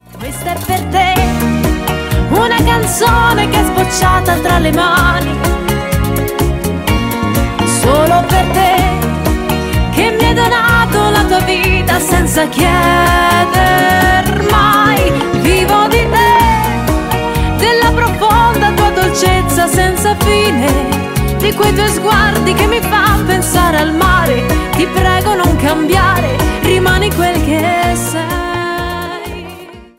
bachata